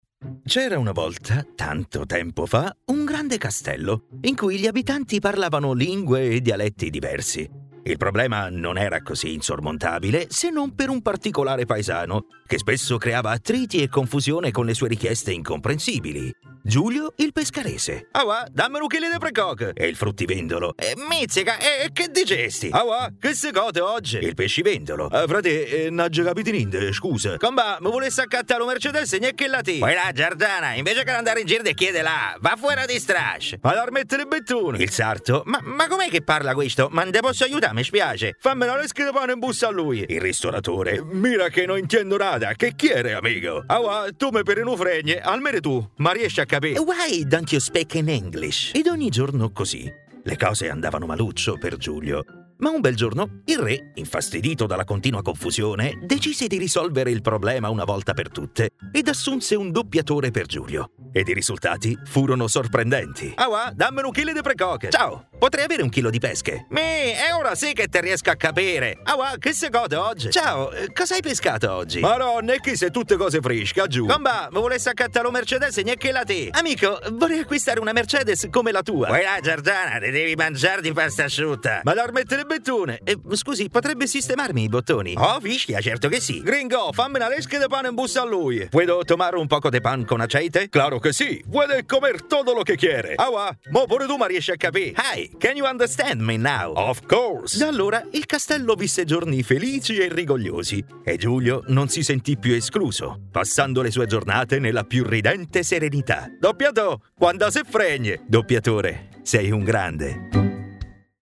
and i'm a Professional Italian Voiceover with baritonal and adaptable voice for each project
Sprechprobe: Sonstiges (Muttersprache):
I have a Home Studio and all the knowledge to edit and send professional, quality audio.